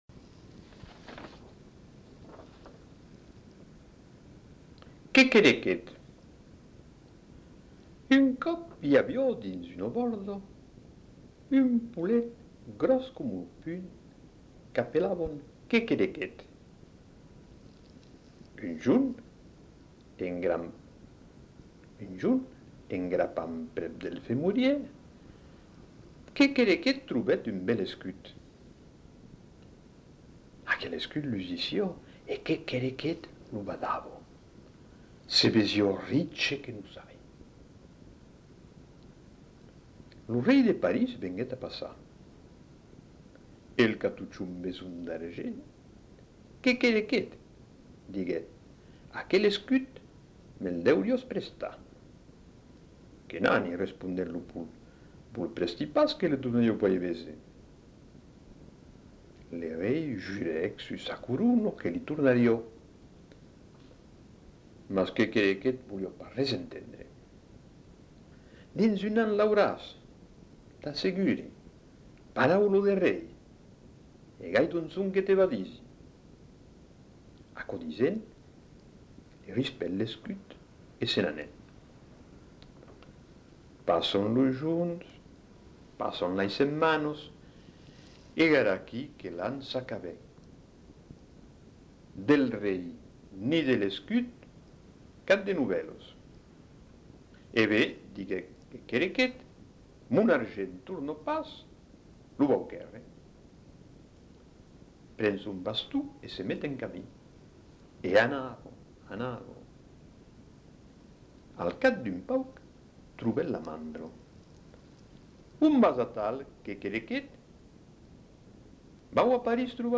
Genre : conte-légende-récit
Effectif : 1
Type de voix : voix d'homme
Production du son : lu